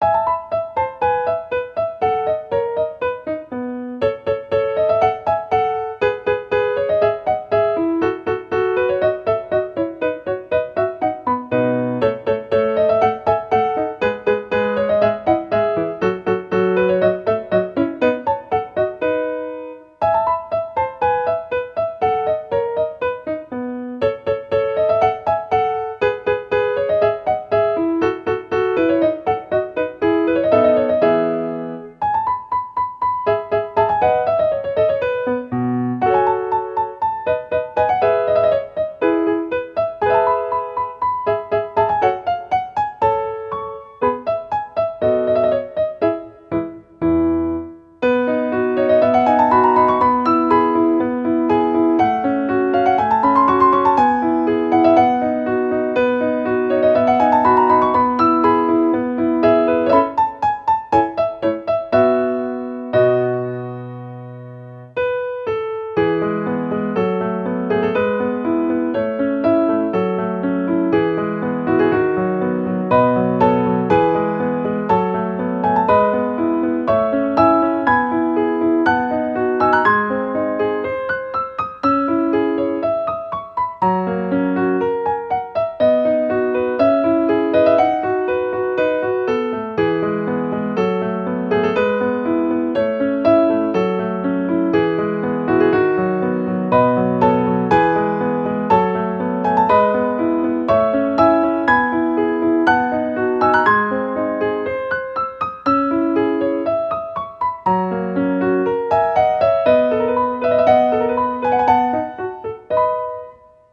ogg(L) - おしゃれ クラシカル 作業
クラシック風なポップピアノ。